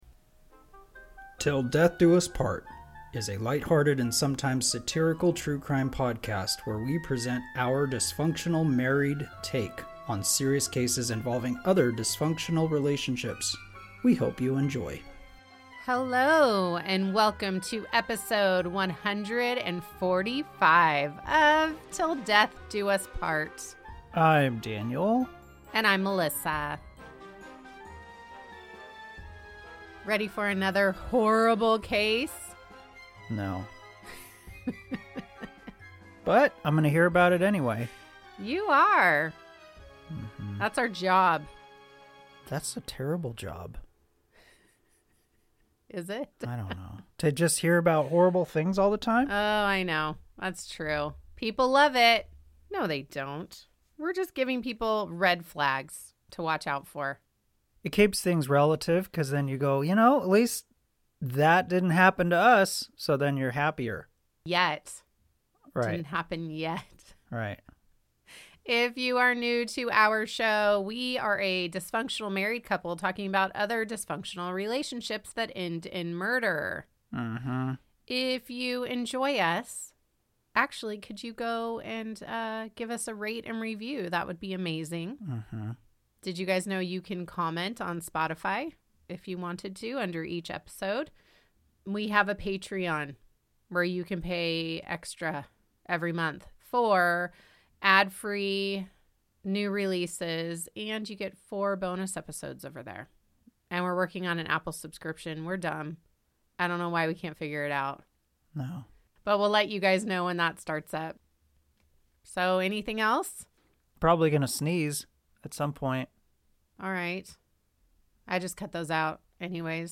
Marriage, Horror, Lifestyle, Halloween, Crime, Husband, Couple, Comedy, True Crime, Society & Culture, Satire, Murder, Wife